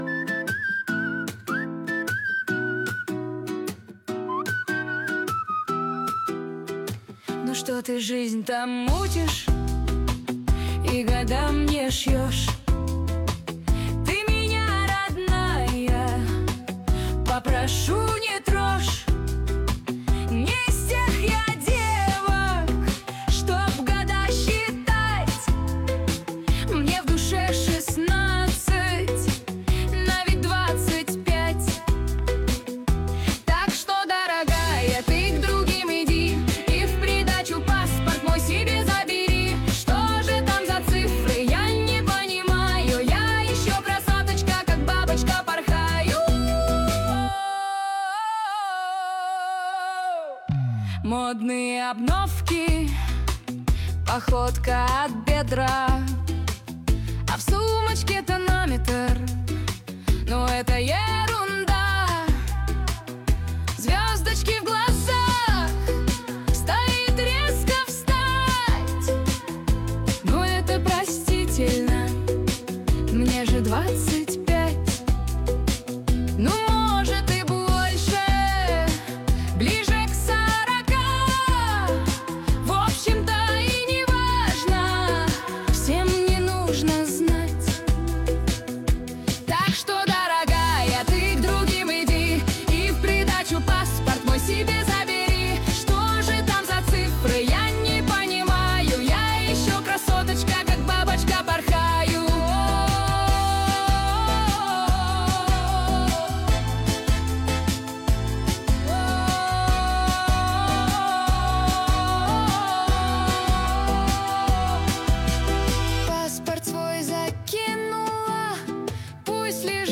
Нейросеть Песни 2025
суно ии песня